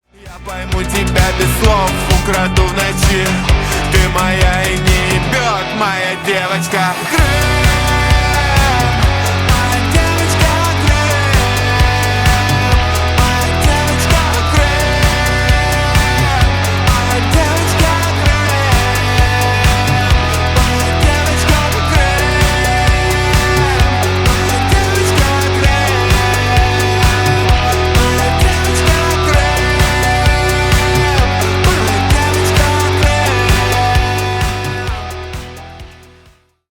• Качество: 320 kbps, Stereo
Рок Металл